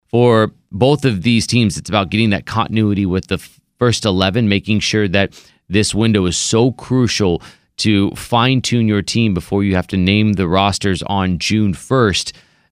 Soccer broadcaster